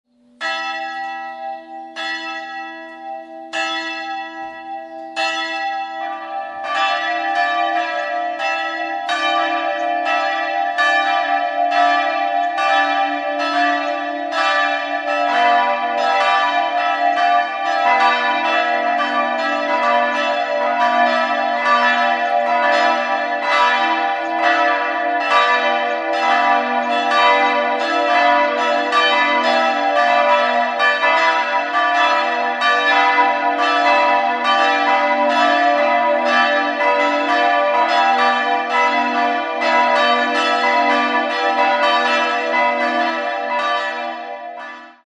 Doch denkt man sich die liturgische Ausstattung weg, würde man diesen Raum aufgrund der tristen Betonflächen eher als Turnhalle als einen Sakralraum empfinden. 3-stimmiges TeDeum-Geläute: h'-d''-e'' Die drei Glocken wiegen 379, 260 und 178 kg und wurden 1977 in der Gießerei Heidelberg gegossen.